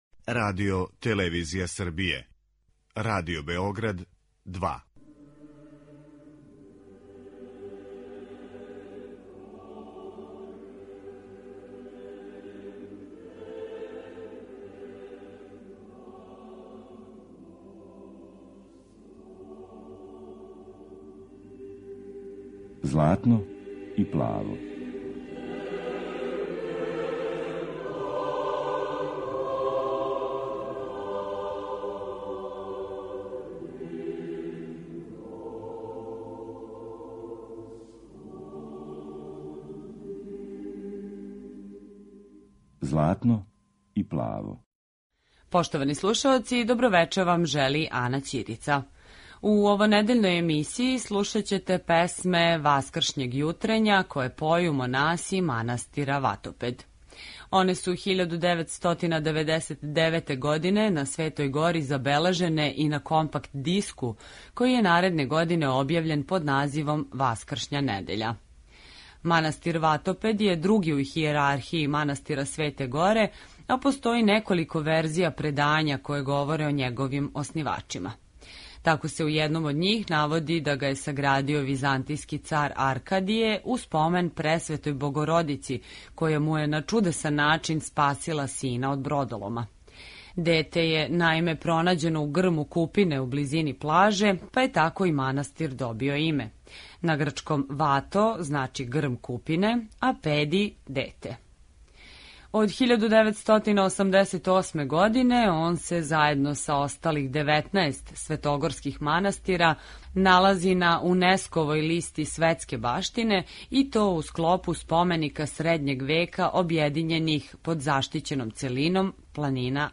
Песме Васкршњег јутрења